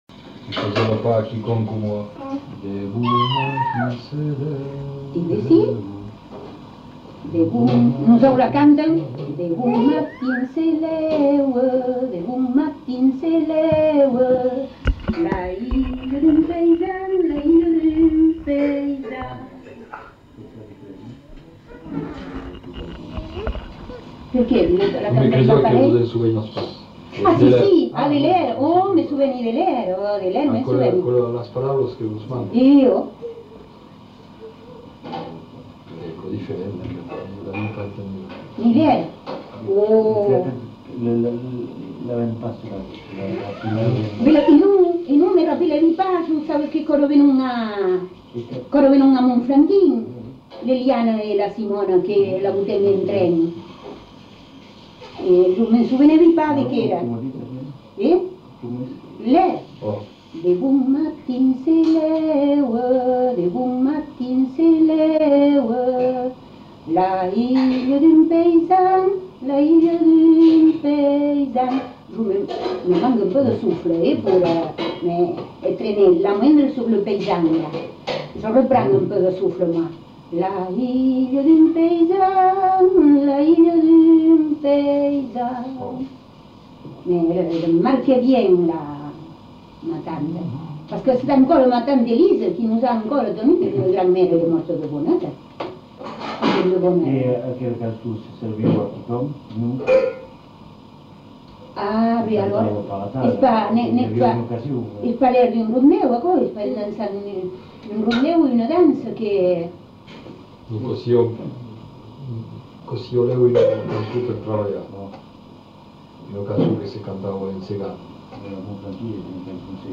Aire culturelle : Marmandais gascon
Lieu : Tonneins
Genre : chant
Effectif : 1
Type de voix : voix de femme
Production du son : chanté
Notes consultables : L'incipit seul est chanté deux fois.